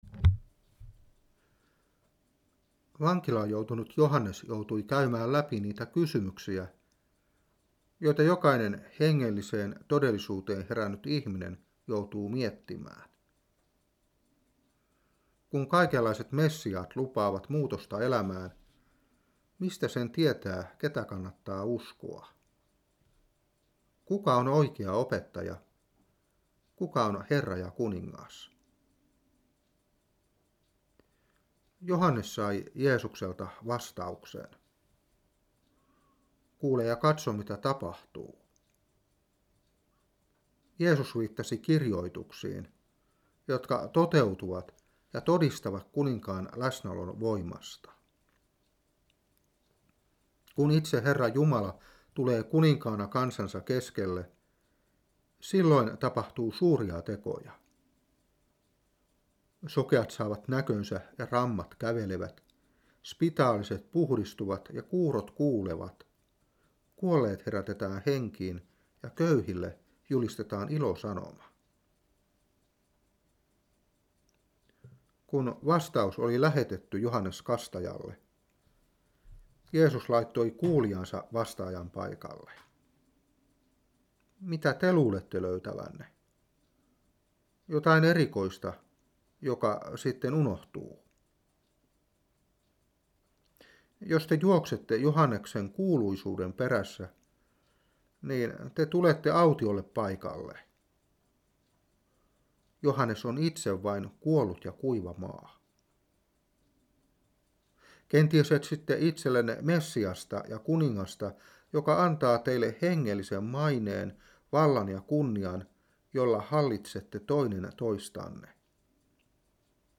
Saarna 2015-12.